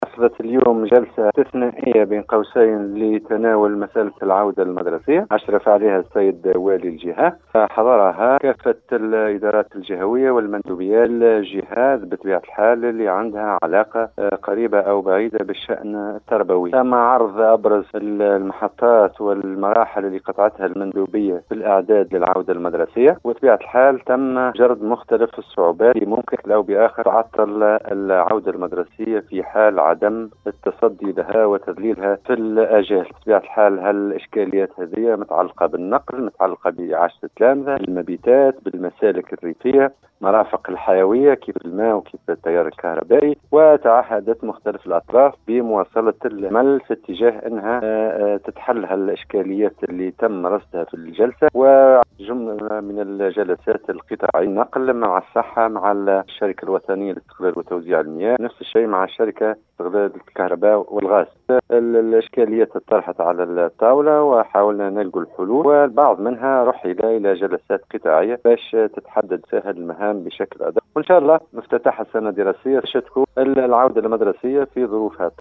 المندوب الجهوي للتربية بالقصرين  منصف القاسمي